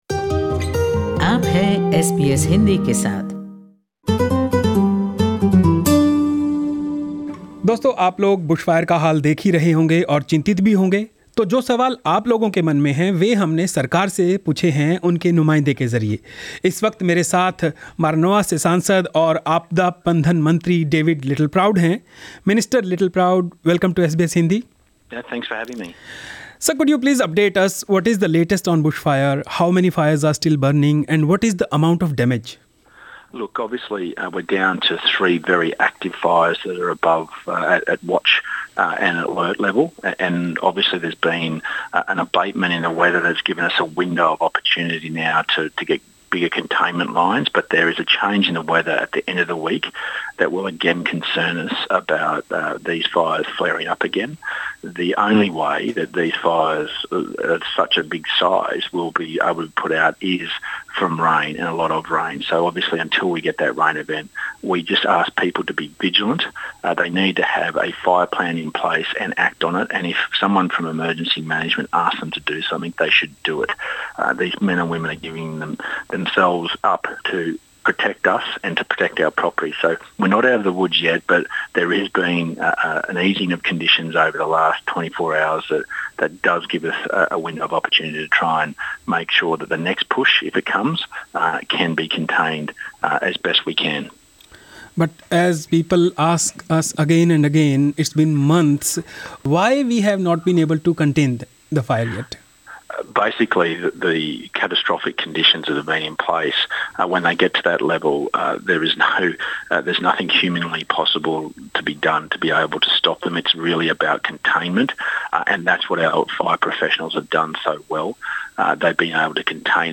David Littleproud told SBS Hindi the Australian people had supported government’s climate policies in the 2019 election, so they will not change amid or after the present bush fire crisis.